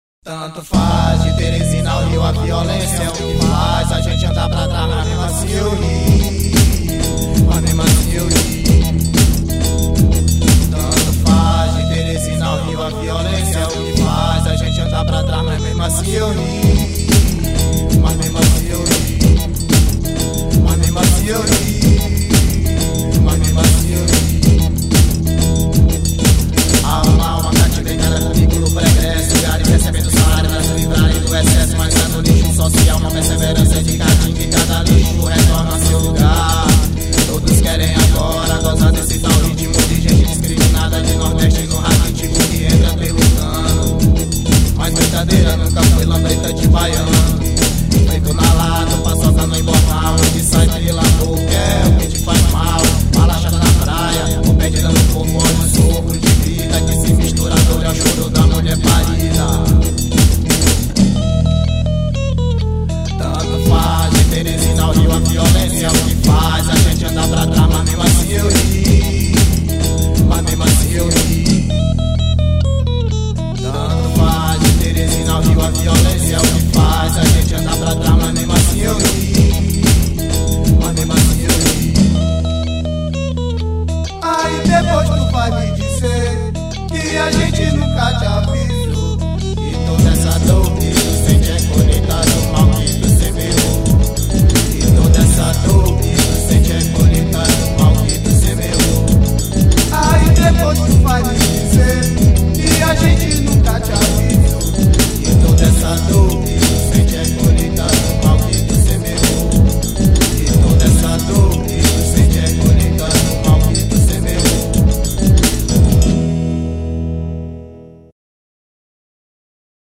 1947   01:59:00   Faixa:     Rock Nacional